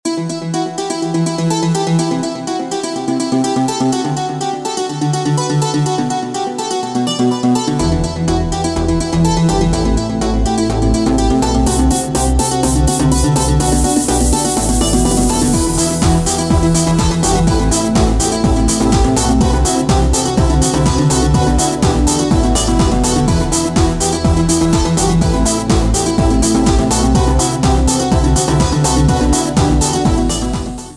Танцевальные
без слов